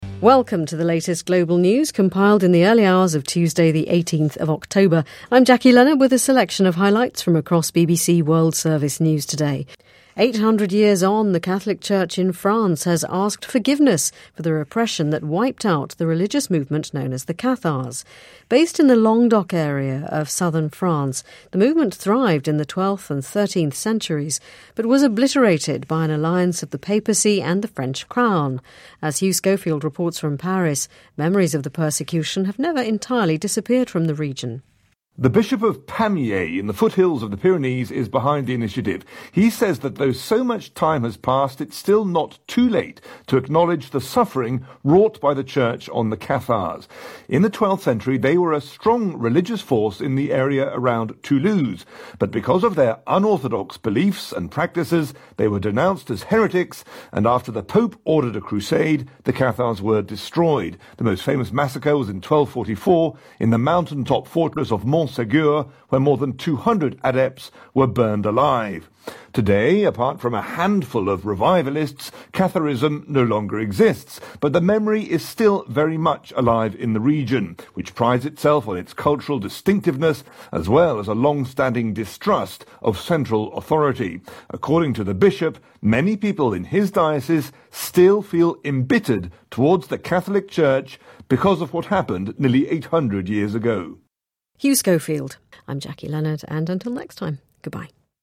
Just the relevant news item